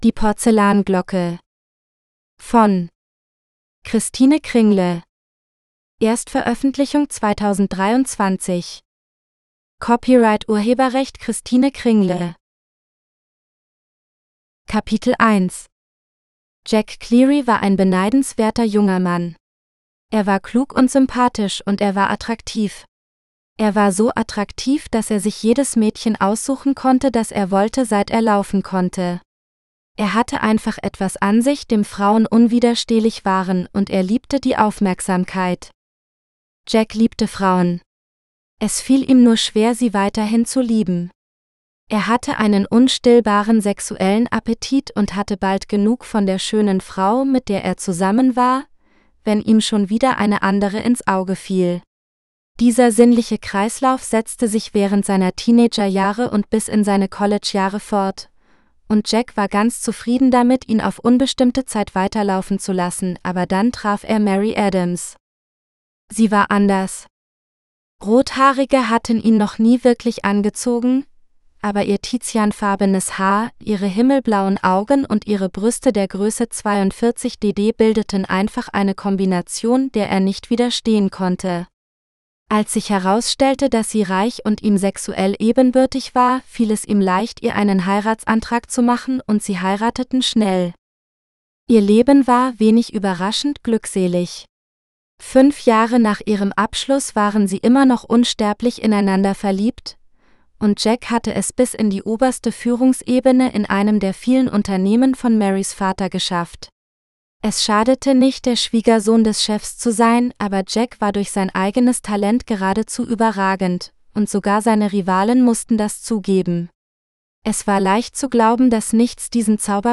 The Porcelain Bell – (AUDIOBOOK – female): $US2.99